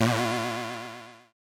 stunned.wav